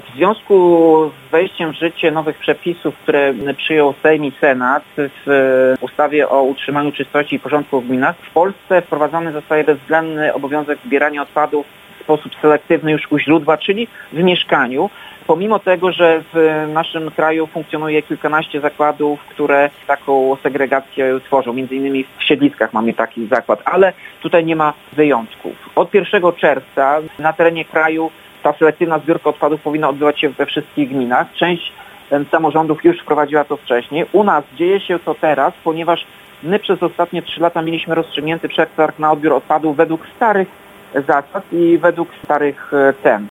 O tym, dlaczego ten fakt niczego nie zmienia- mówi Tomasz Andrukiewicz, prezydent Ełku.